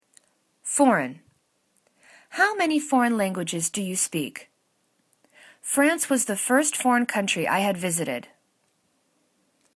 for.eign    /forәn/     adj